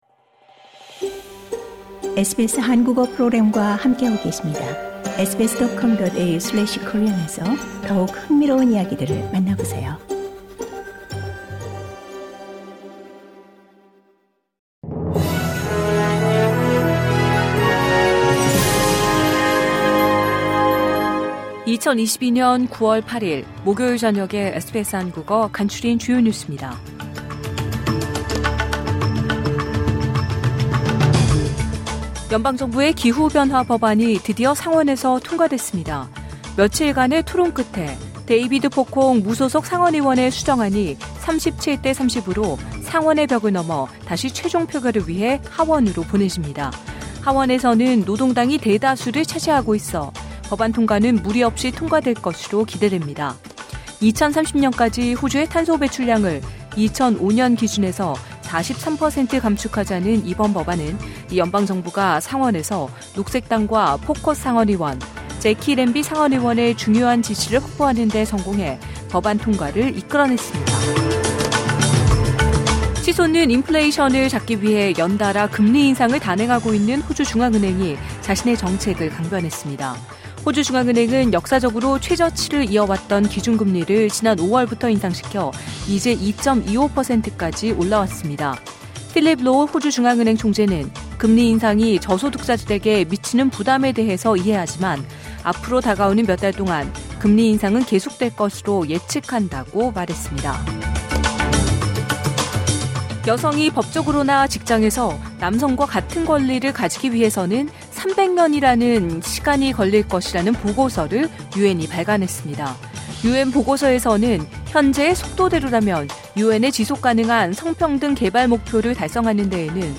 2022년 9월 8일 목요일 저녁 SBS 한국어 간추린 주요 뉴스입니다.